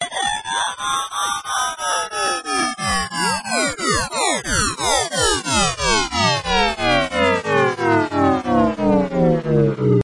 描述：tono de despertador para movil
Tag: 消息 呼叫 电话 细胞 警报 移动 手机 UEM